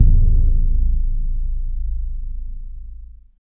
Index of /90_sSampleCDs/Roland LCDP03 Orchestral Perc/PRC_Orch Bs Drum/PRC_Orch BD Roll